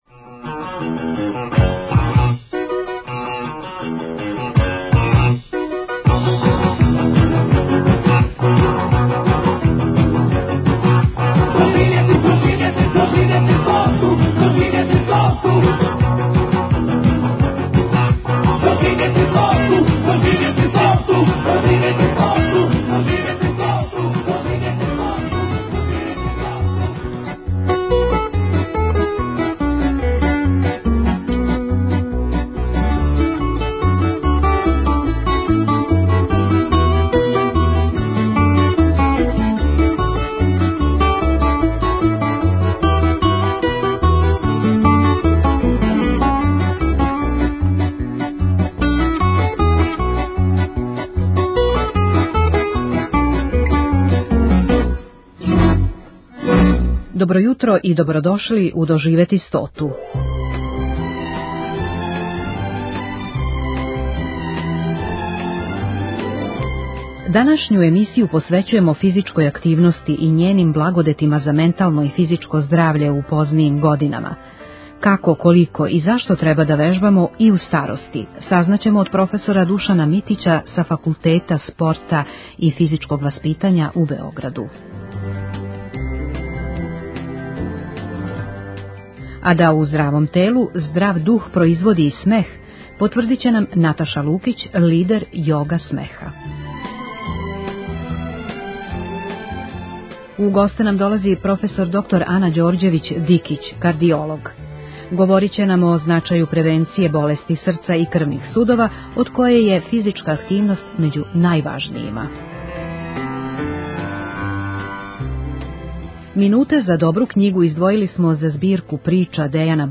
доноси интервјуе и репортаже посвећене старијој популацији